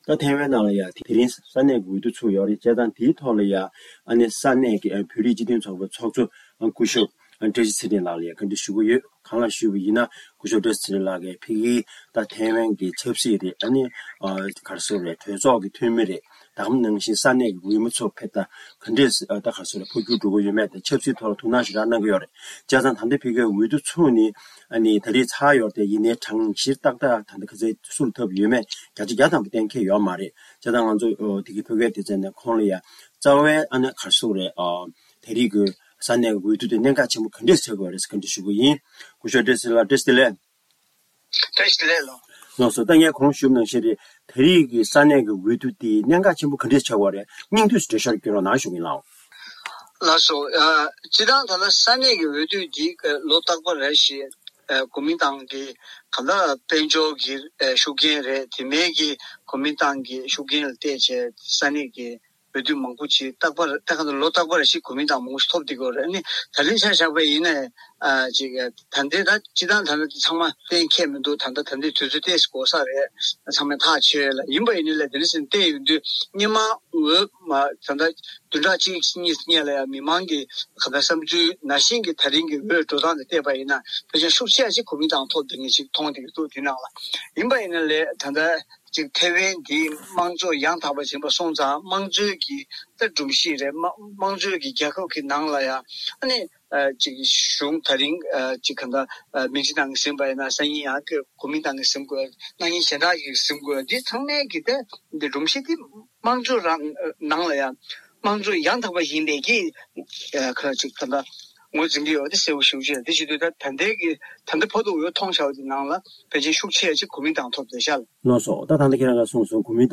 བརྙན་འཕྲིན་ལེ་ཚན་ཁག རླུང་འཕྲིན་ལེ་ཚན་ཁག